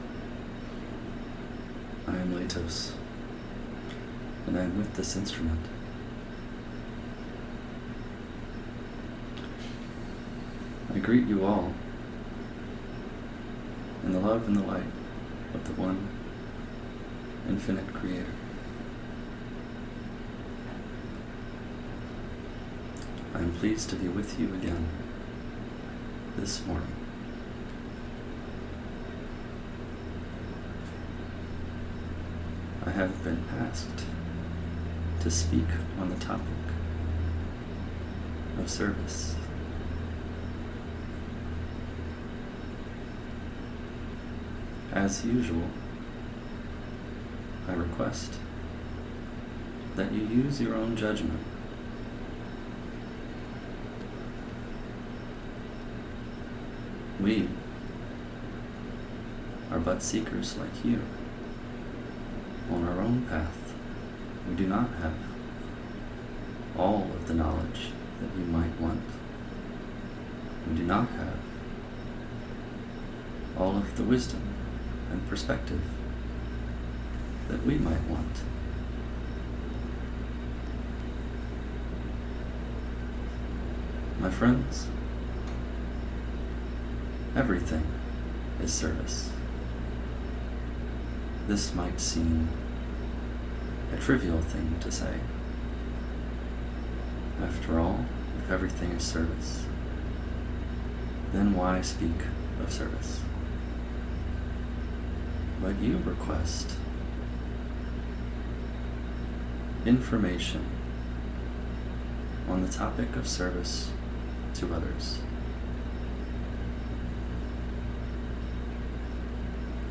In this session from the Other Selves Working Group’s first channeling intensive, those of Laitos explore several dimensions of service to others.